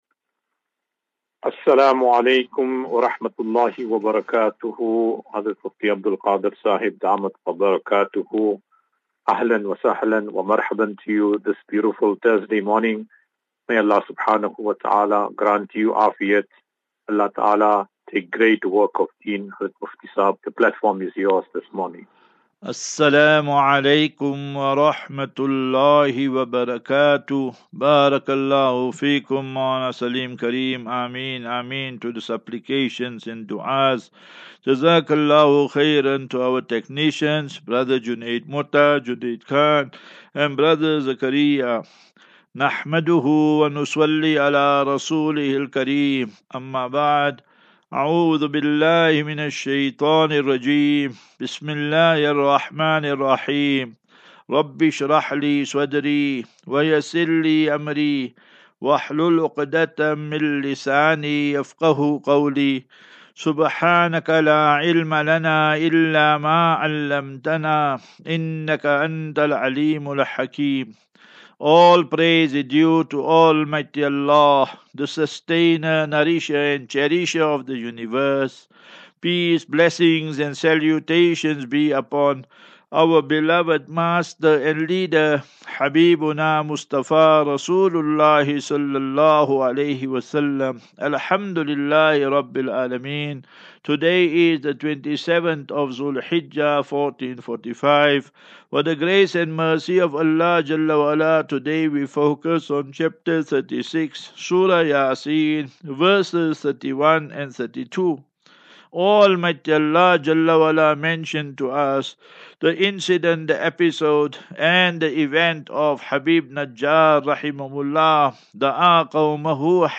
4 Jul 04 July 2024. Assafinatu - Illal - Jannah. QnA